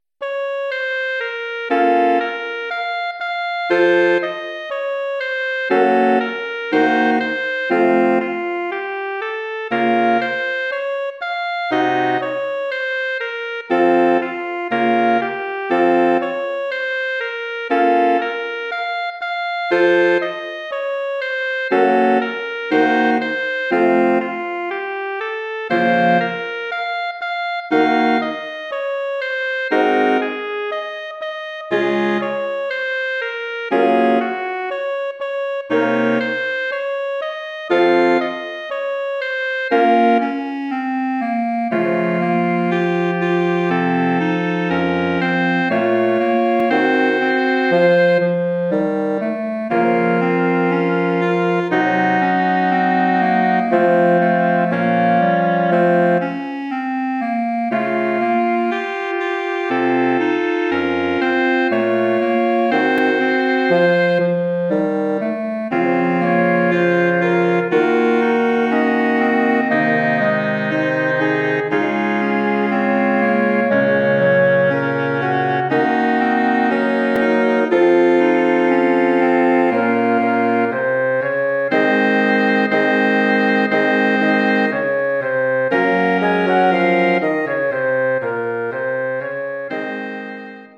Bearbeitung für Bläserquintett
Besetzung: Flöte, Oboe, Klarinette, Horn, Fagott
arrangement for woodwind quintet
Instrumentation: flute, oboe, clarinet, horn, bassoon